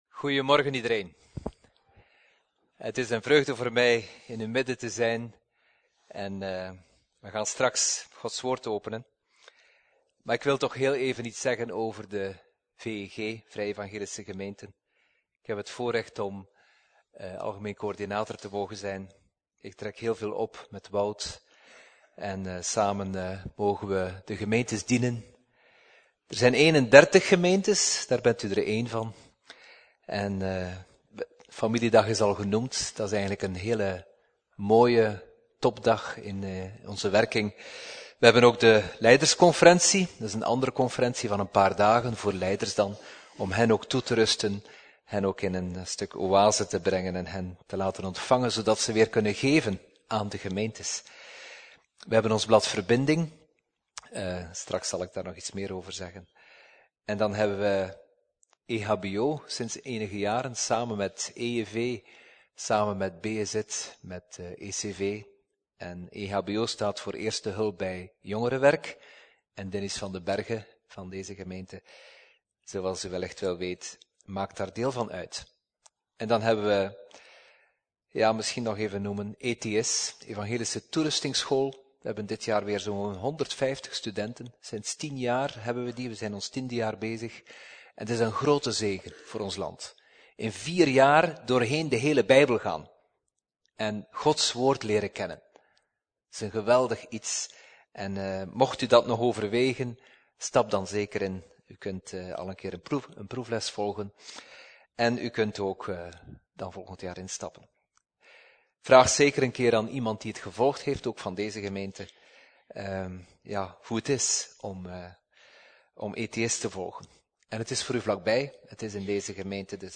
Preek: Ontdekken van Gods wil - Levende Hoop